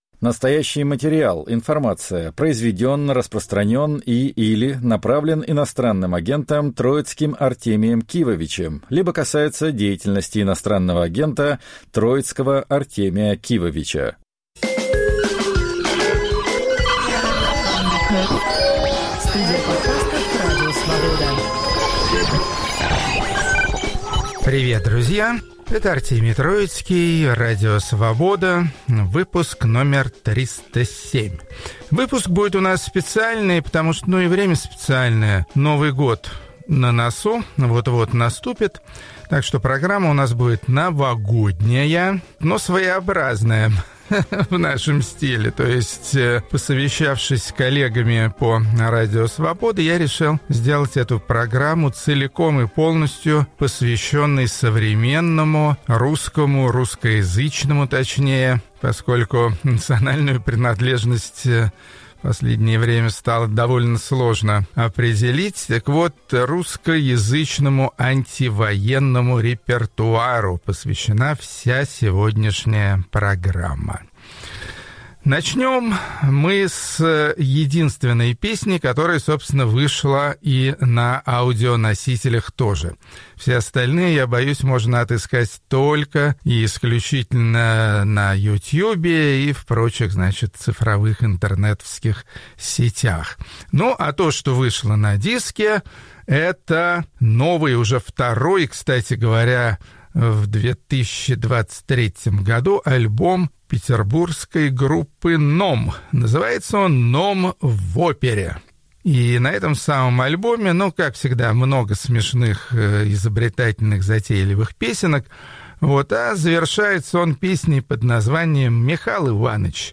В 307-м выпуске программы и подкаста "Музыка на Свободе" Артемий Троицкий поздравляет слушателей с Новым годом и предлагает послушать песни о мире, где Путина нет.